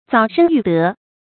澡身浴德 zǎo shēn yù dé
澡身浴德发音